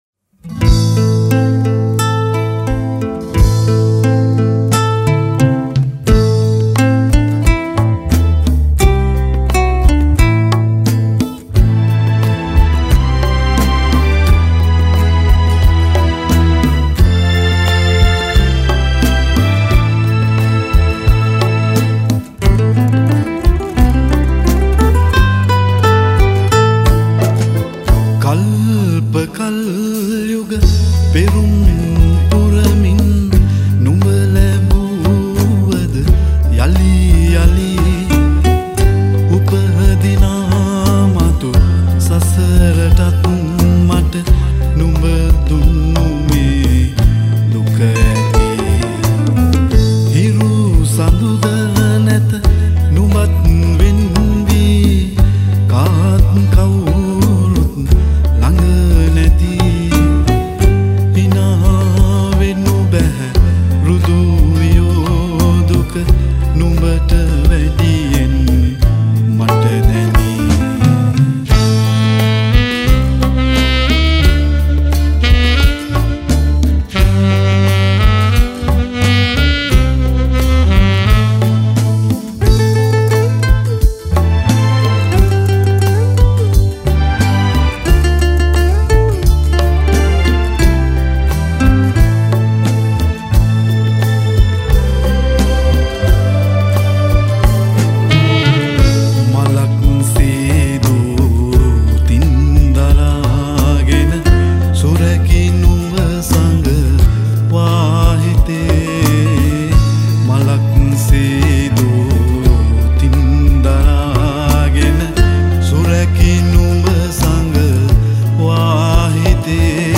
at Sumeega Studio Panadura Sri Lanka